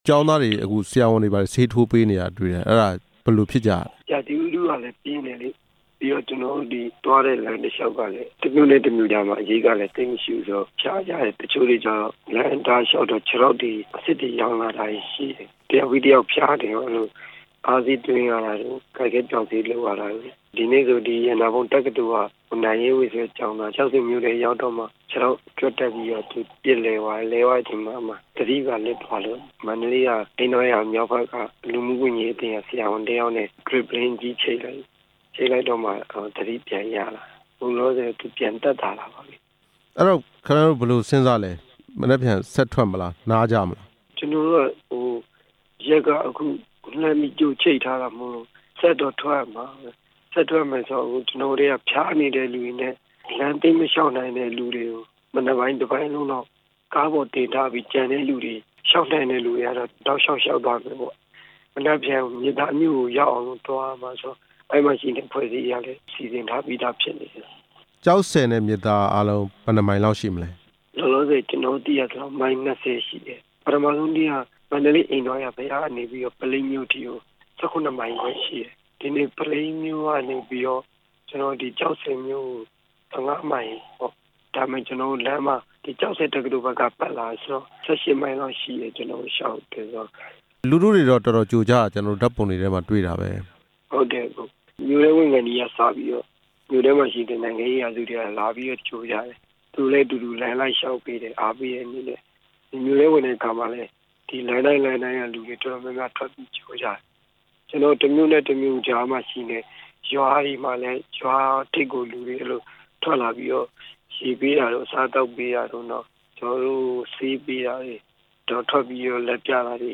ရန်ကုန်ကို ချီတက်ဆန္ဒပြတဲ့ မန္တလေးကျောင်းသားတွေ အကြောင်း မေးမြန်းချက်